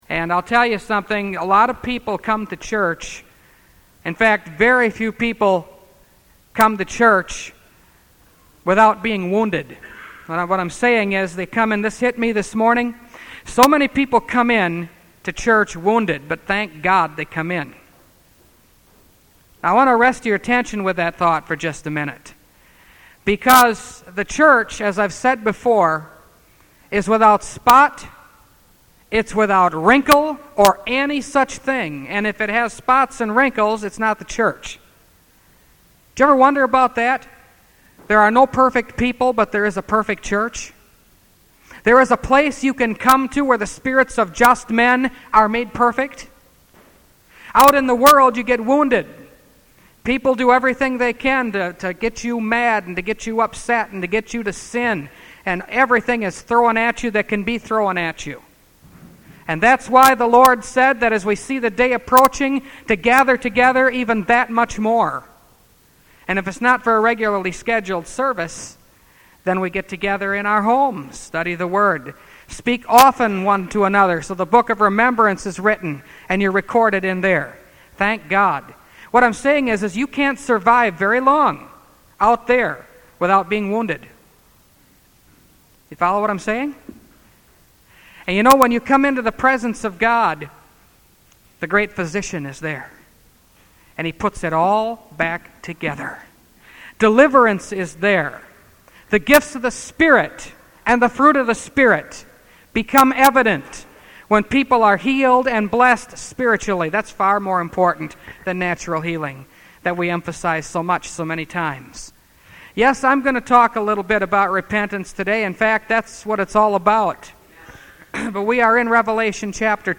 Revelation Series – Part 12 – Last Trumpet Ministries – Truth Tabernacle – Sermon Library